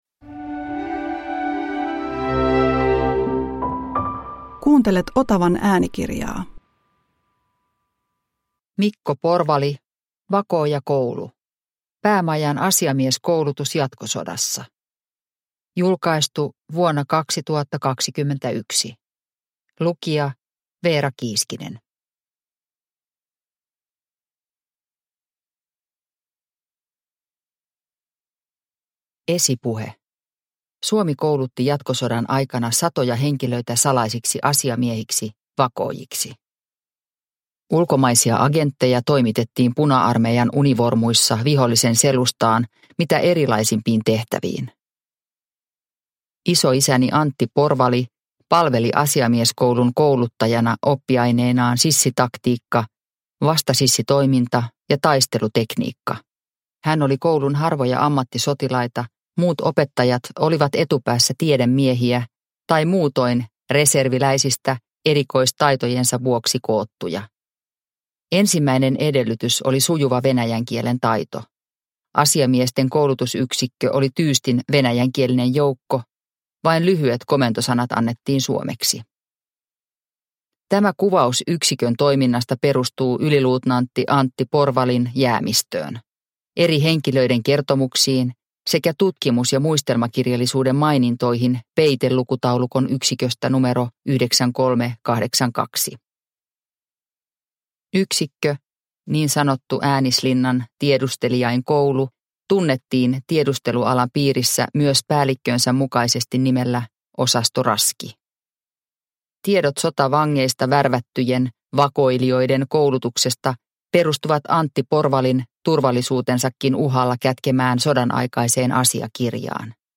Vakoojakoulu – Ljudbok – Laddas ner